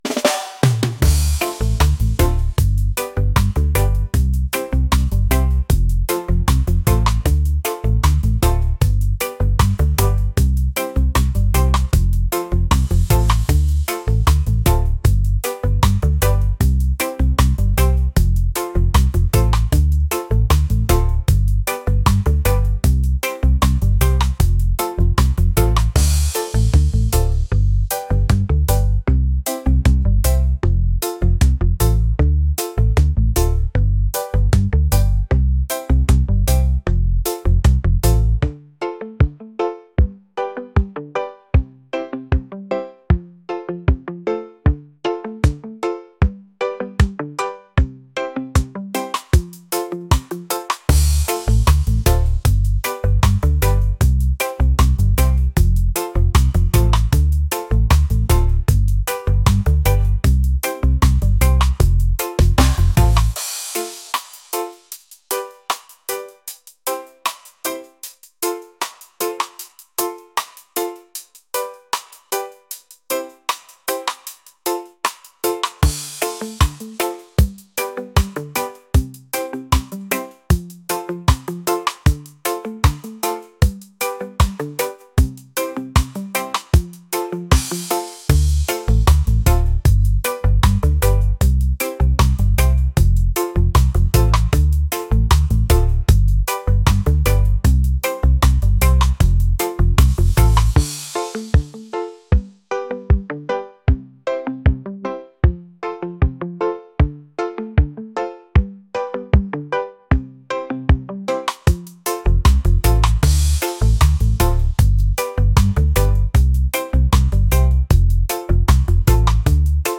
laid-back | island | reggae